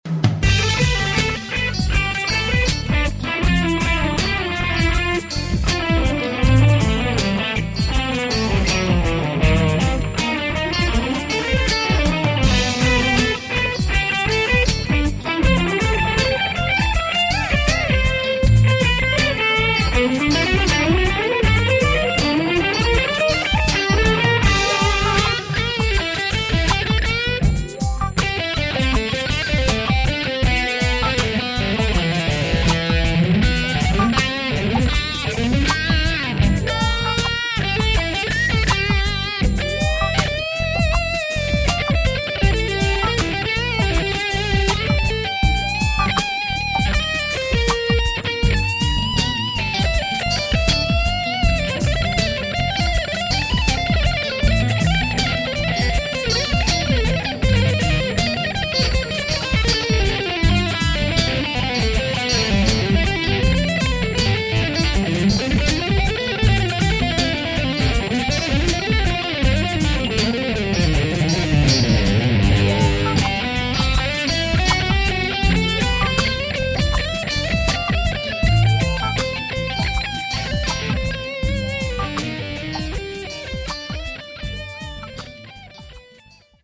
Il tema
Innanzitutto osserviamo la partitura del tema completo, sviluppato su due chorus da quattro battute ciascuno, di cui potete anche ascoltarne la parte
Prevede infatti una serie di note ripetute a sedicesimi, sviluppate su di una diteggiatura aperta in tema con l'argomento trattato in questa pagina.
Nei primi movimenti troviamo una serie di slide discendenti, che "colorano" il sound delle note con riferimenti tipici allo stile di Steve Vai.
Ecco all'inizio della battuta uno sviluppo verticale della linea melodica, basato sulla scala di Eb lidio (IV° della scala di Bb) che culmina con un fraseggio a sestina di sedicesimi su due corde non adiacenti.